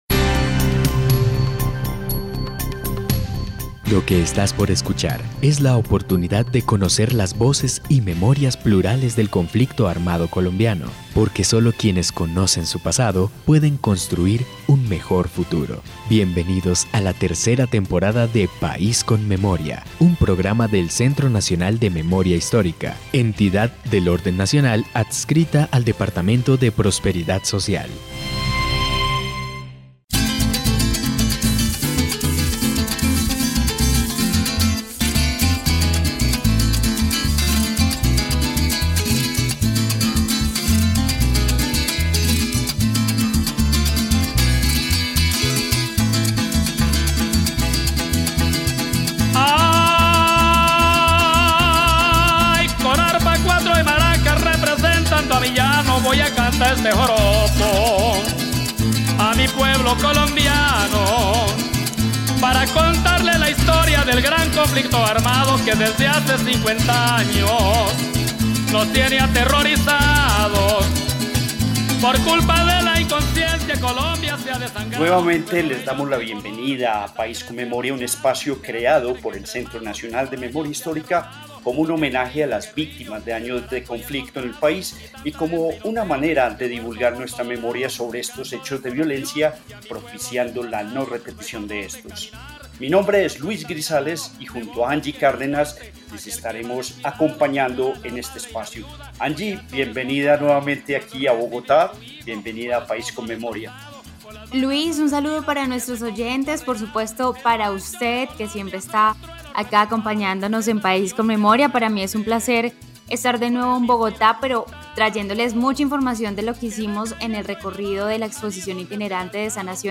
El País con Memoria presentamos un par de entrevistas realizadas en este recorrido.
Audiencia (dcterms:audience) General Descripción (dcterms:description) Capítulo número 33 de la tercera temporada de la serie radial "País con Memoria". En el parque principal de Colón, Putumayo, terminó el recorrido del camión itinerante del Museo de Memoria de Colombia por seis municipios del suroccidente del país.